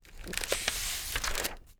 TURN PAGE6-S.WAV